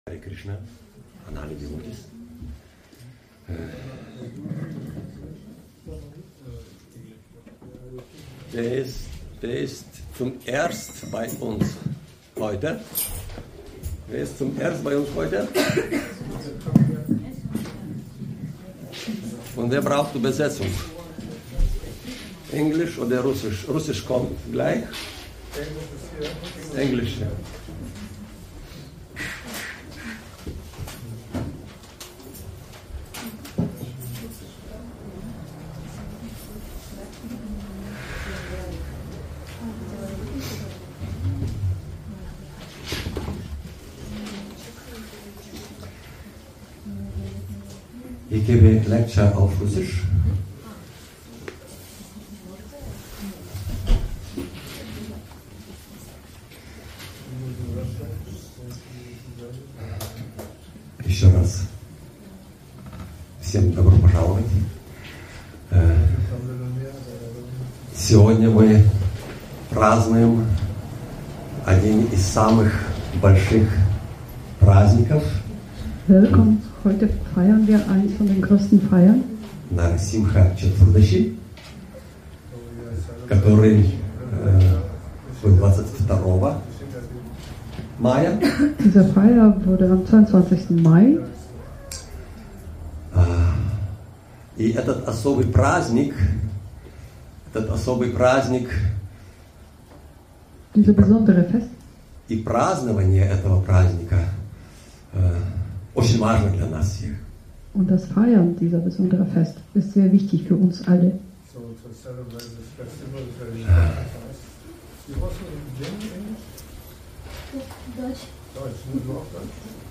Vortrag zu Srimad Bhagavatam 7.9.8